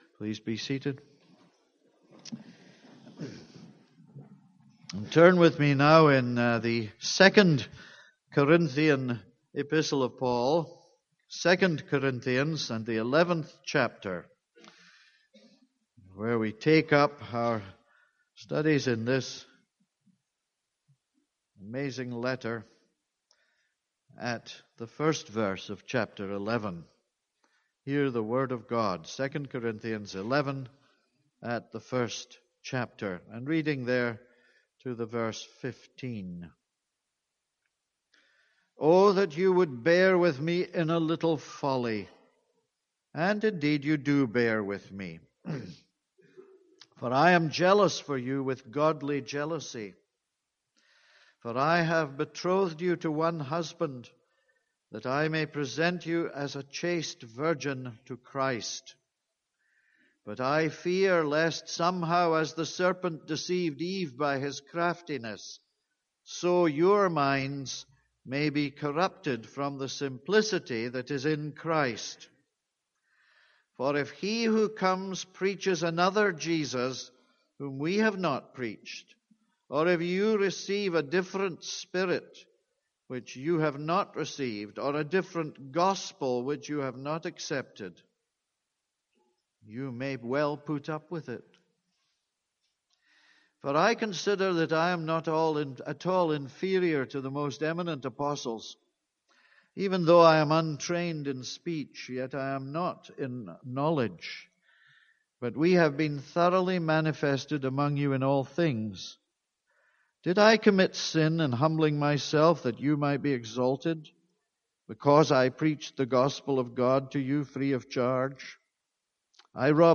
This is a sermon on 2 Corinthians 11:1-4.